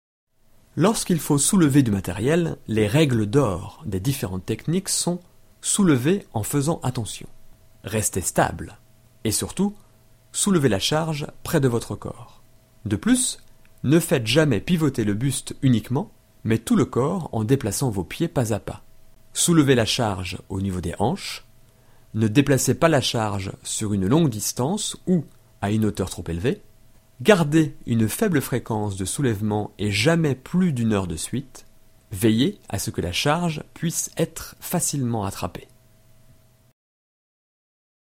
Sprecher französisch.
Sprechprobe: eLearning (Muttersprache):
French voice over talent.